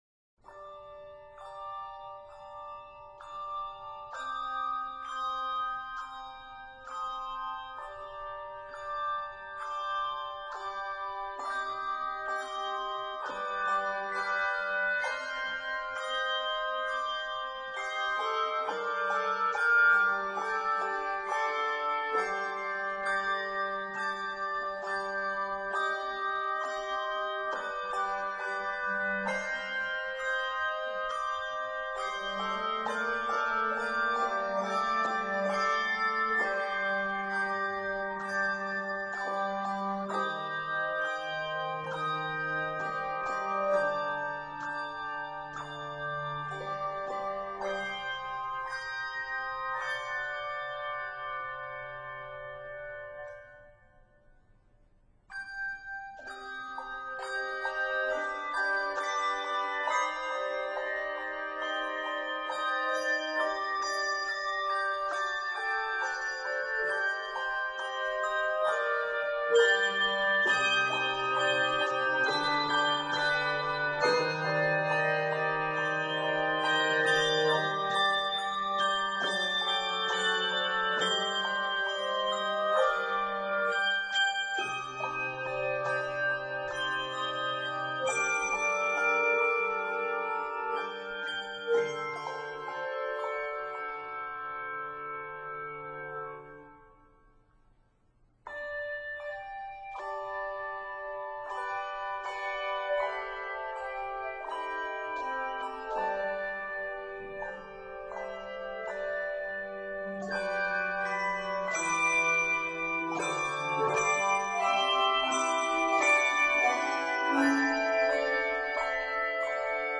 reflective 76 measure Lenten medley